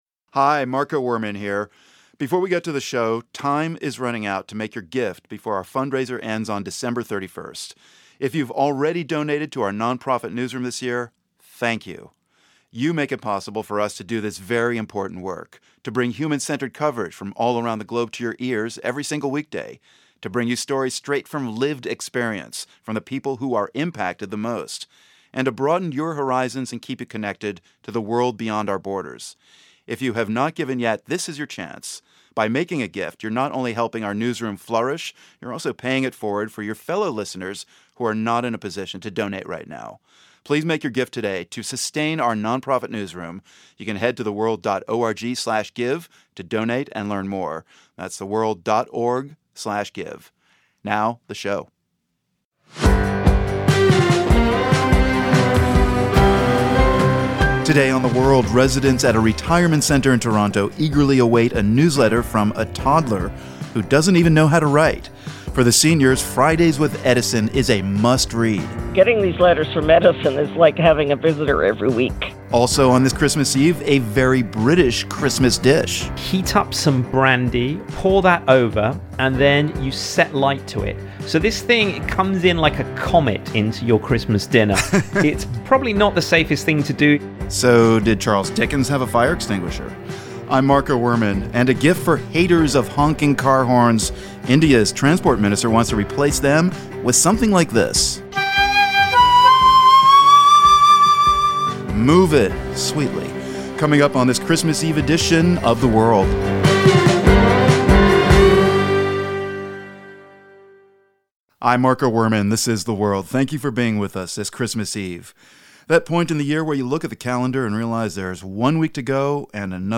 The pandemic — and social isolation — has been especially hard on people in long-term care. We hear from a toddler and his mom in Canada who wanted to bring a little joy to residents at a retirement home.
We hear responses from all over the world to that age-old question.